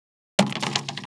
Index of /traerlab/AnalogousNonSpeech/assets/stimuli_demos/jittered_impacts/small_rubber_longthin_yellowrubbertubing